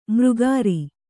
♪ mřgāri